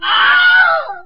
wilhelm-scream.wav